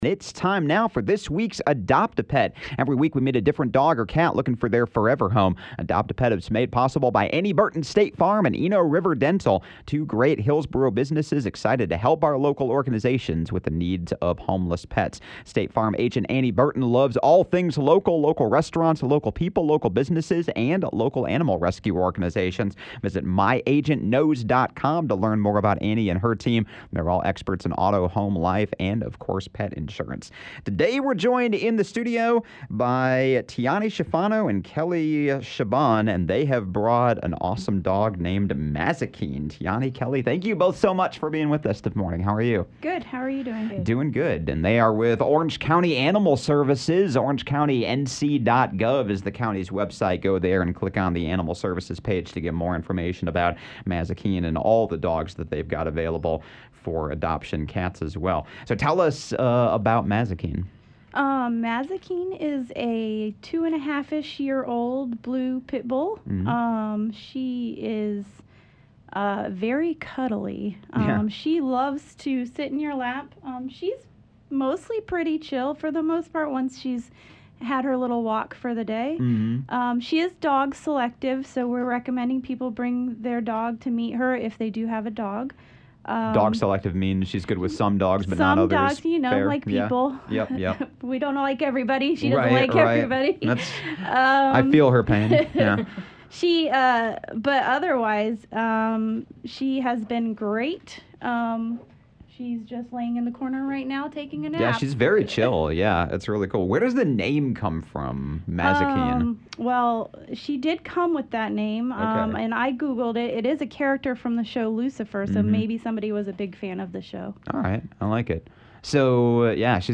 To learn more about Mazikeen, and Orange County Animal Services, listen to the Adopt-A-Pet on-air segment below!